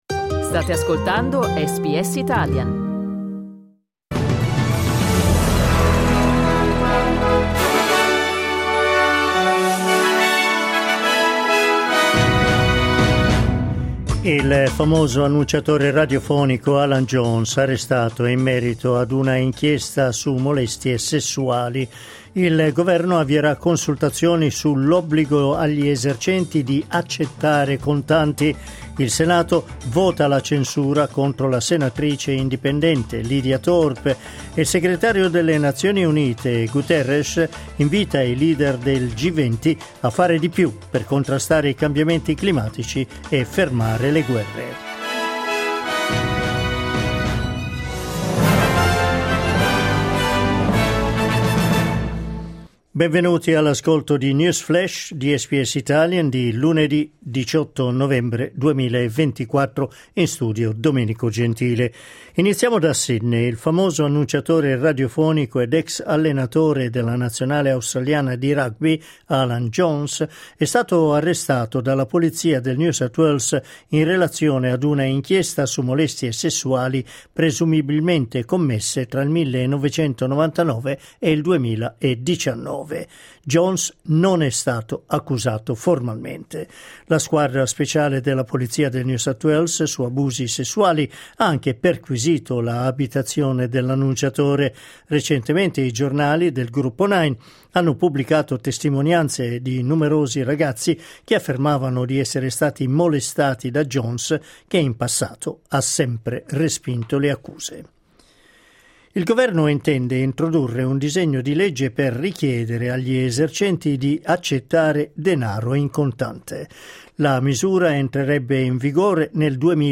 News flash lunedì 18 novembre 2024